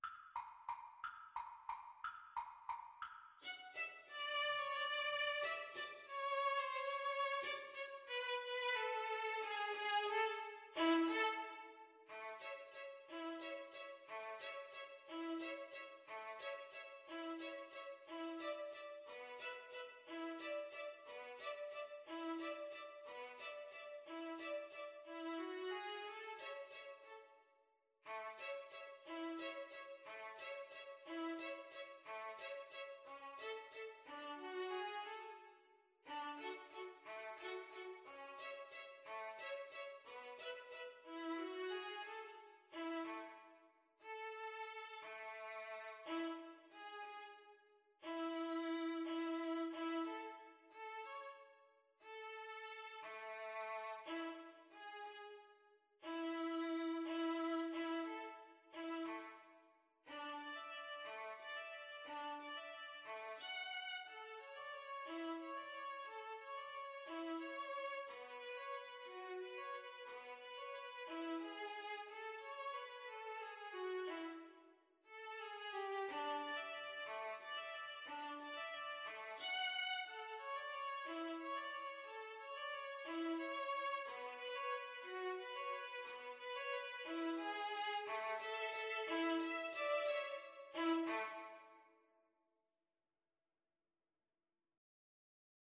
Violin 1Violin 2
One in a bar .=c.60
3/4 (View more 3/4 Music)